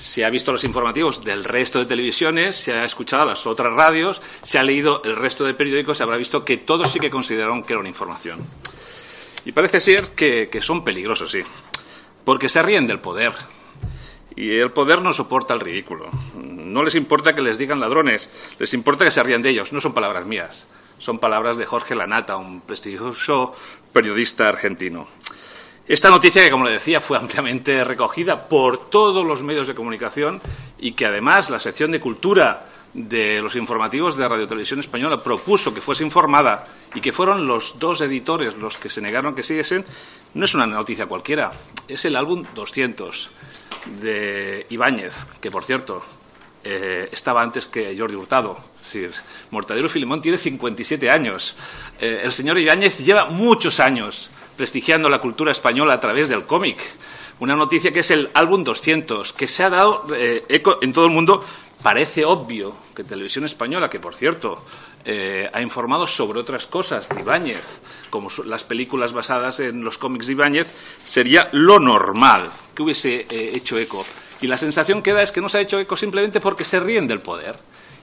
Fragmento de la intervención de José Zaragoza en la Comisión de Control del RTVE 28/04/2015